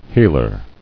[heel·er]